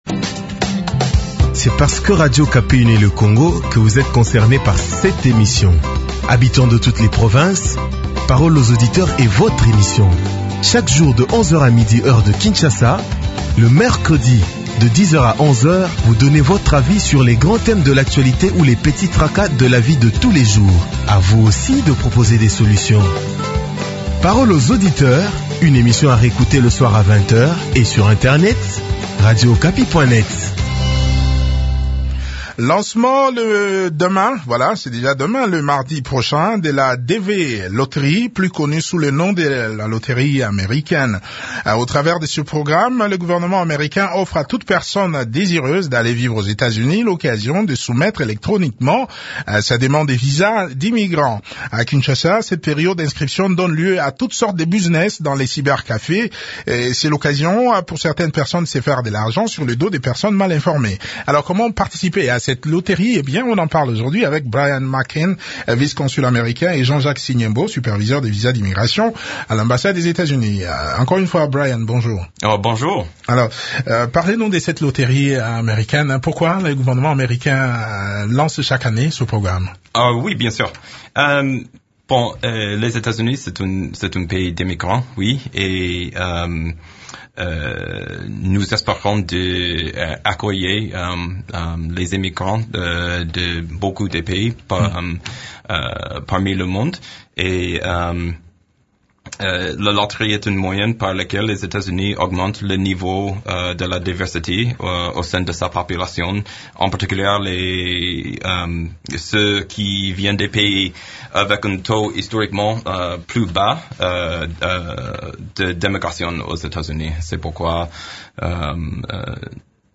répondent aux questions des auditeurs